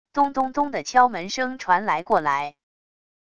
咚咚咚的敲门声传来过来wav音频